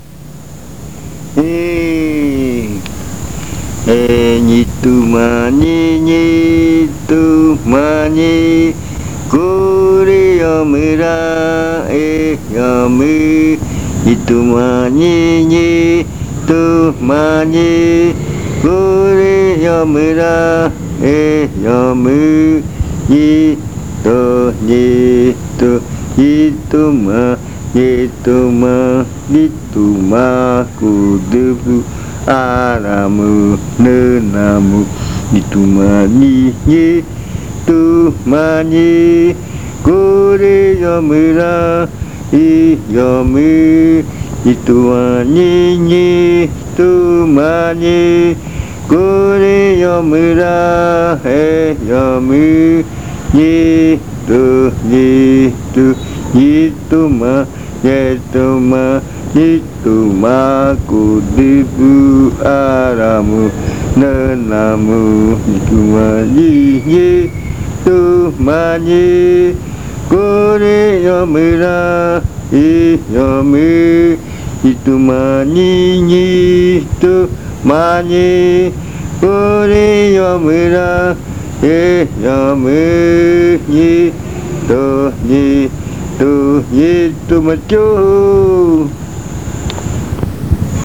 Ñitumaiñi, canto dedicado al astro sol. El cantor murui canta esta canción con mucho entusiasmo animando a los bailarines, hombres y mujeres, para que bailen con alegría.
Este canto hace parte de la colección de cantos del ritual Yuakɨ Murui-Muina (ritual de frutas) del pueblo Murui
The murui singer sings this chant with great enthusiasm encouraging the dancers, men and women, to dance with joy.